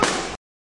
SFK一个简单的小号/拍子的声音和一些变化 " 原始的声音
描述：snaree，拍手，sfx打击乐打击乐一次性perc percssive
Tag: 敲击 拍手 的PERC snaree 打击乐器 SFX 打一拍 四氯乙烯